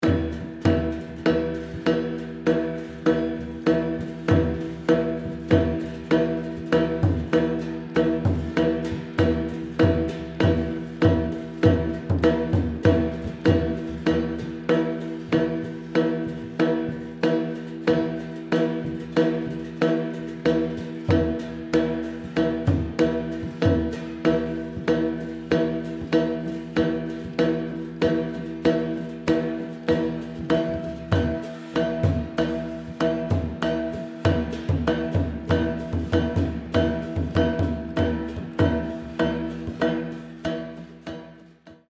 temple gongs the Shan use the same gong machine as the Northern Thai, here with a temple drum 655KB
Shan gongs.mp3